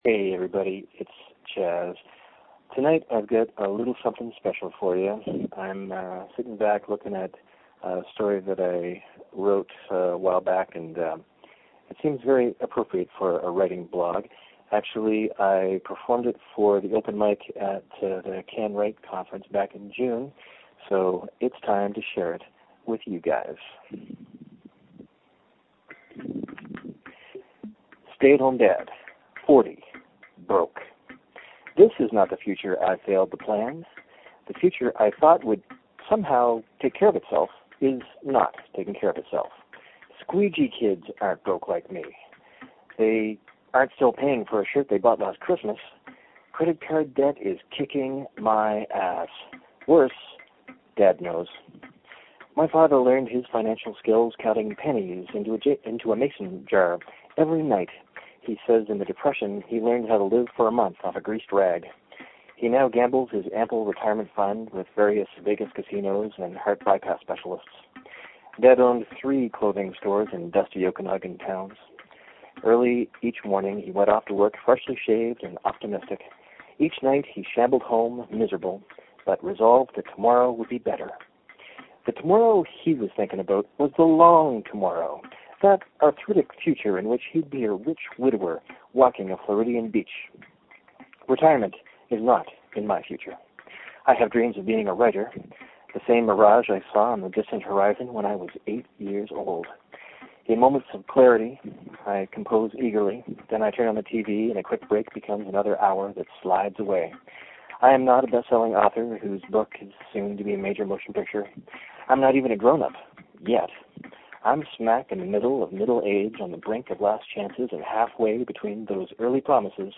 I read this story at the open mic at the CanWrite conference in June.
It’s five minutes and I can perform it rather than just read it, so I hope it will go over well.